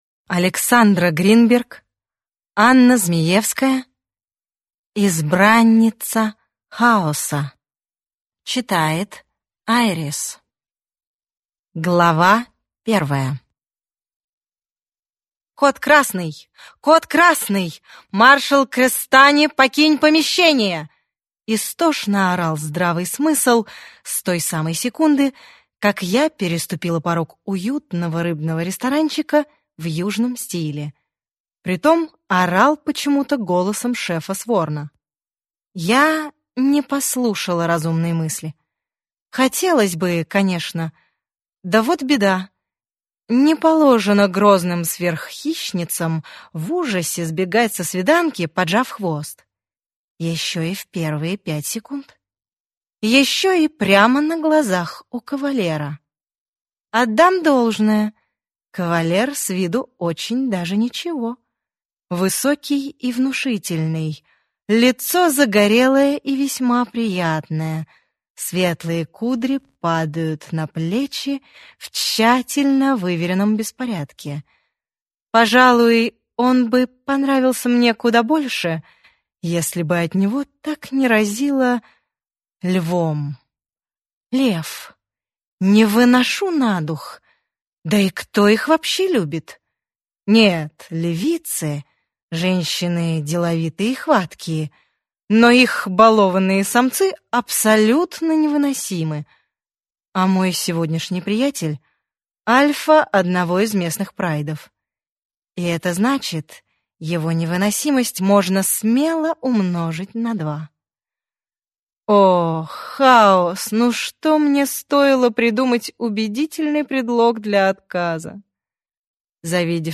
Аудиокнига Избранница Хаоса | Библиотека аудиокниг